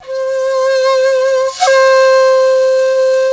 ZG FLUTE 1 L.wav